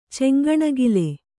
♪ ceŋgaṇagile